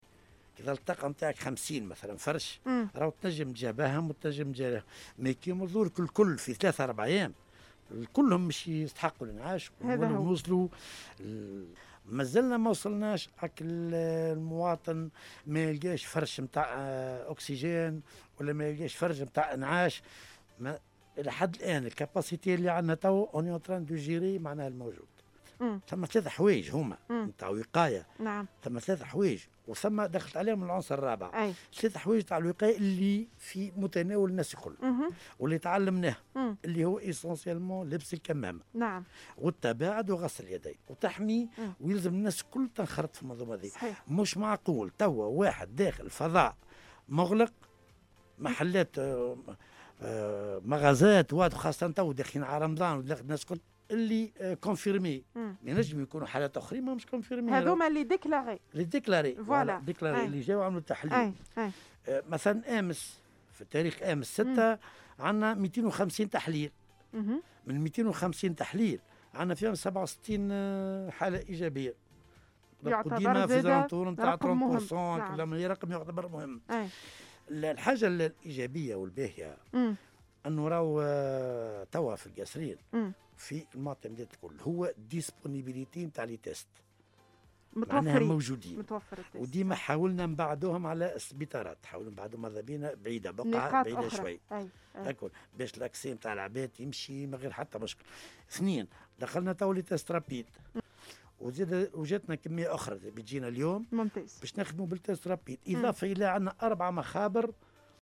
/// تصريح